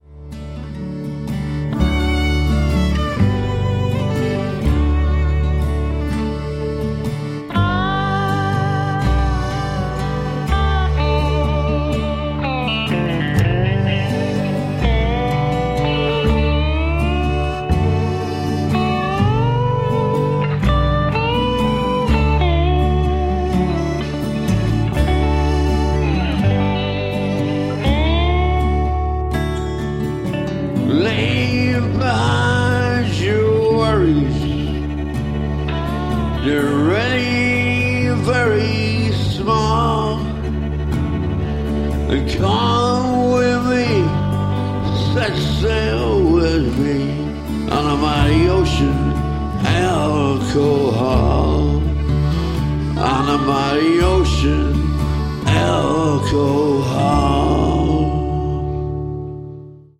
pijacka piosenka